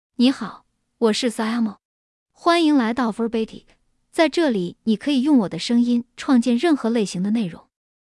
Xiaomo — Female Chinese (Mandarin, Simplified) AI Voice | TTS, Voice Cloning & Video | Verbatik AI
Xiaomo is a female AI voice for Chinese (Mandarin, Simplified).
Voice sample
Listen to Xiaomo's female Chinese voice.
Xiaomo delivers clear pronunciation with authentic Mandarin, Simplified Chinese intonation, making your content sound professionally produced.